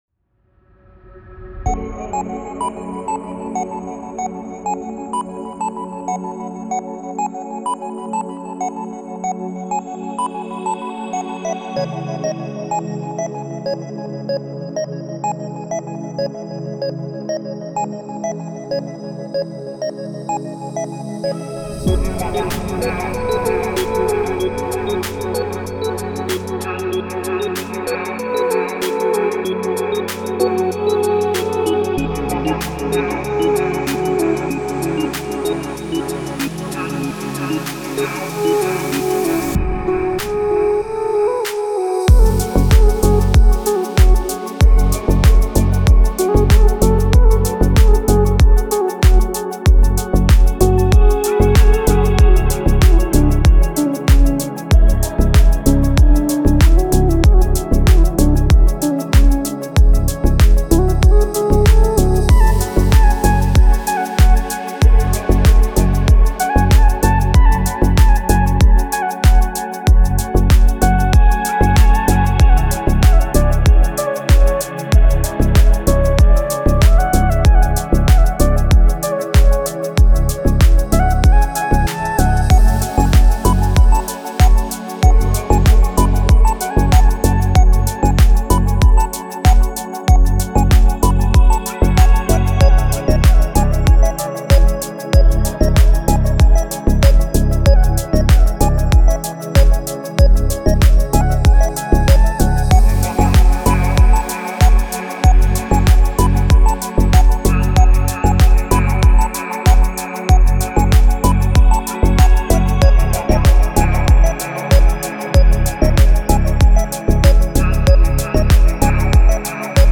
Спокойная музыка
спокойные треки